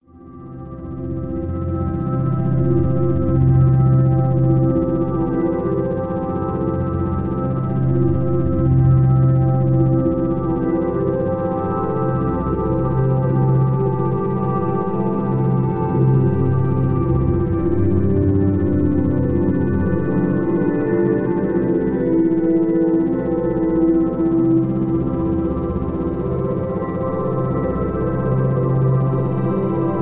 Types of Entrainment Sounds: Isochronic Beat
Background Sounds/Environment: Ambient Music
Frequency Level: Only Beta